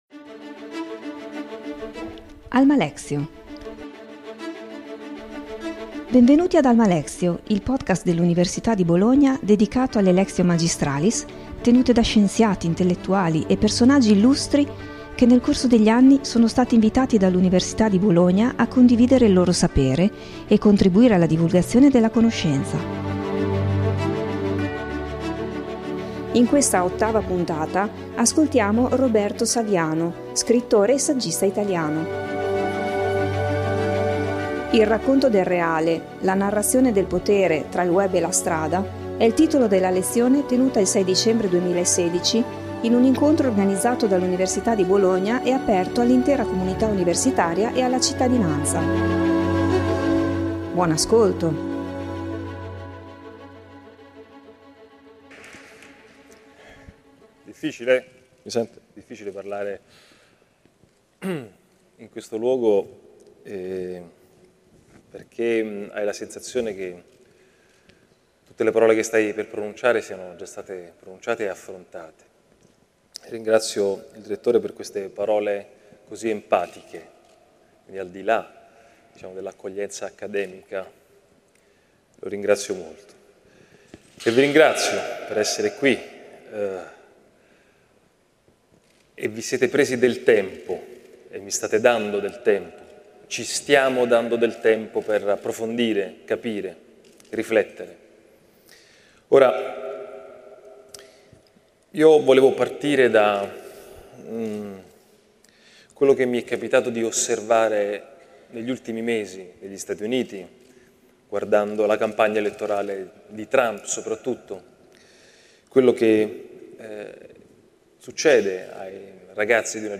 Roberto Saviano è uno scrittore, giornalista e sceneggiatore italiano.Nei suoi scritti, articoli e nel suo romanzo Gomorra, utilizza la letteratura e il reportage per raccontare la realtà economica, di territorio e d'impresa della camorra e della criminalità organizzata in senso più generale. Il 6 dicembre 2016 è stato ospite dell'Ateneo per un “racconto del reale” aperto all'intera comunità universitaria e alla cittadinanza.